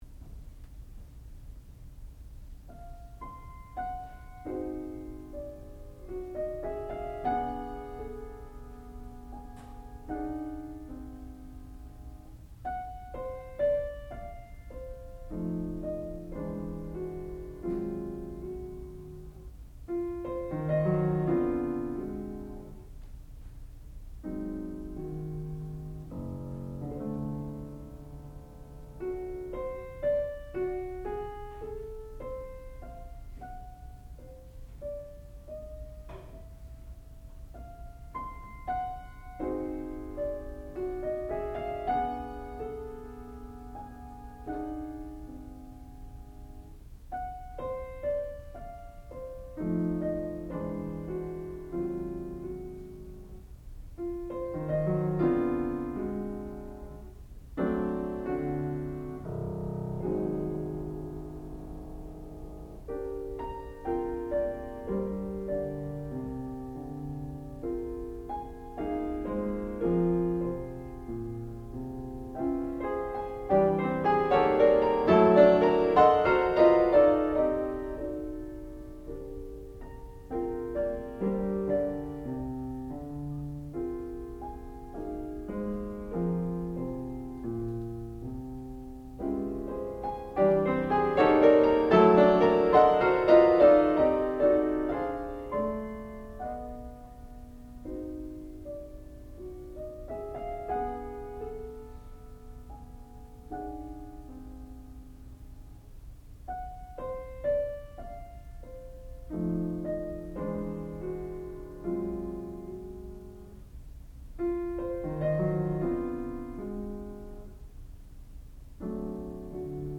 sound recording-musical
classical music
piano
Master's Degree Recital